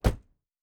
Car Door (1).wav